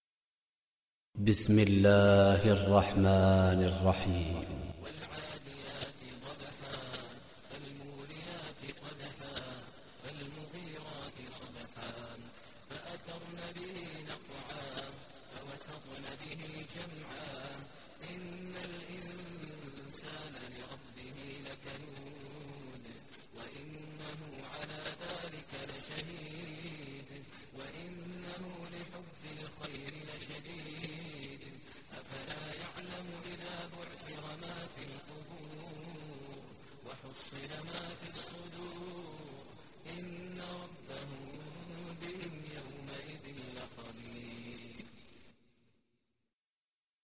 Surah Al Adiyat Recitation by Maher Mueaqly
Surah Adiyat, listen online mp3 tilawat / recitation in Arabic recited by Imam e Kaaba Sheikh Maher al Mueaqly.